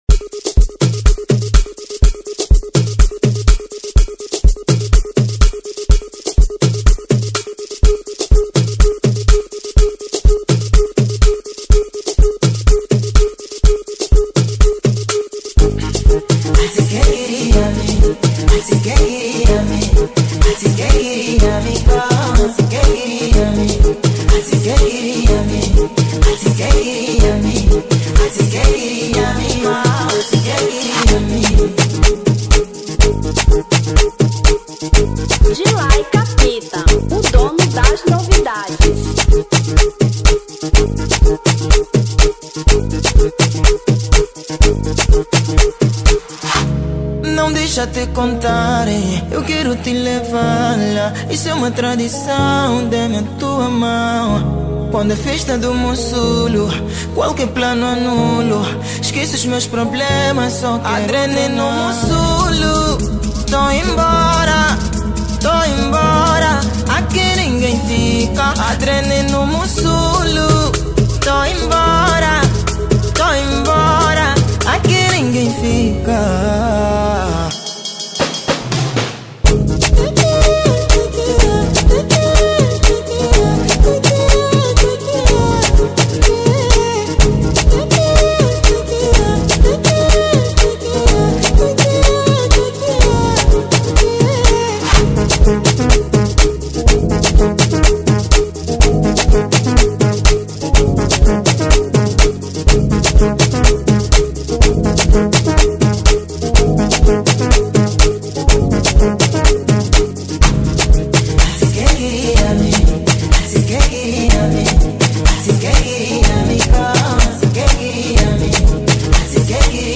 Original Mix 2025